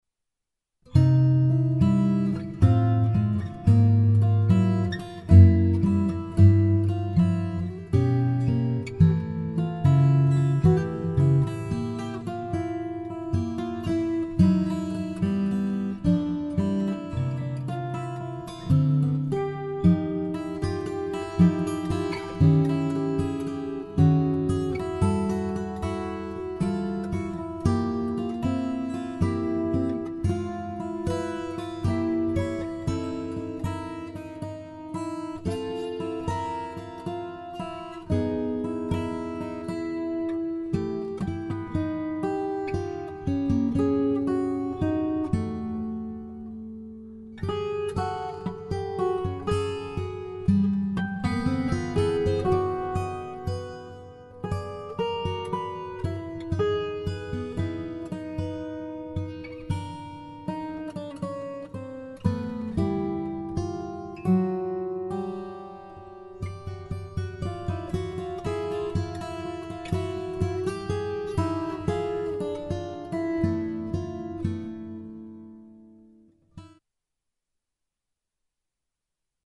for 6-string and 12-string guitar duet